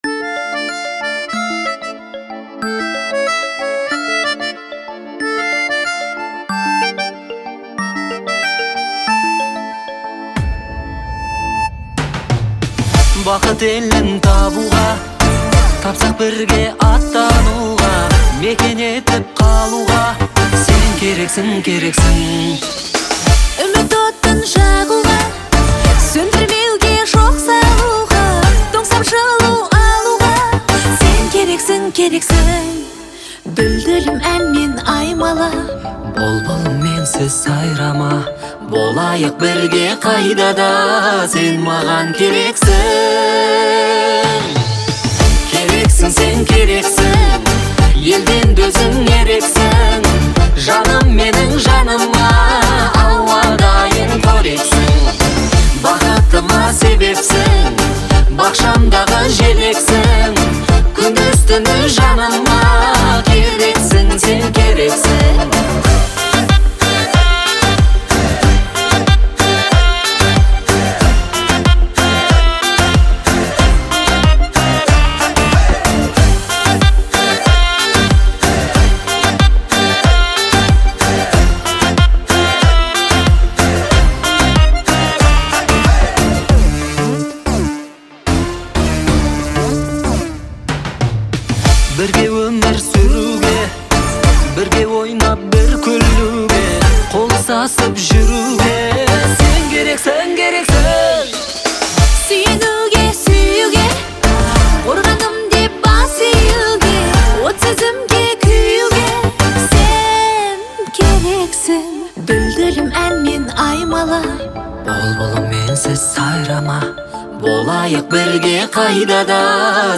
в исполнении дуэта